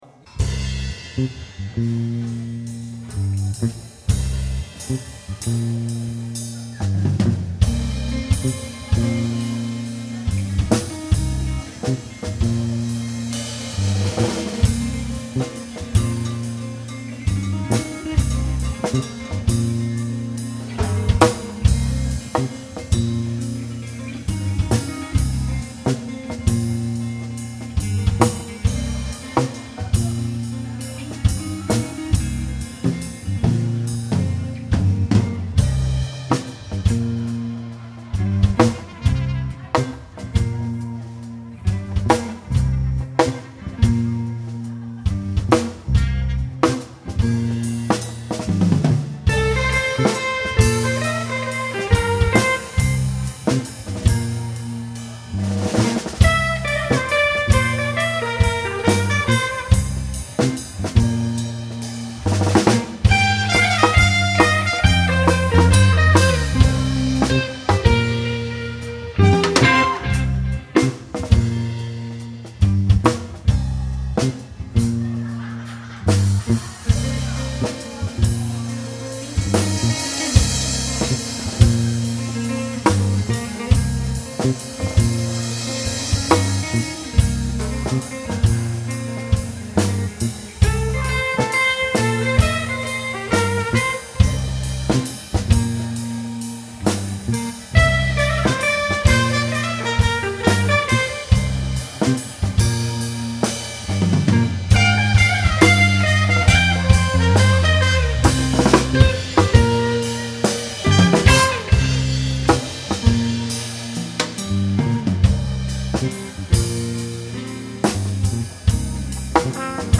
Quartet - Live at York St. Cafe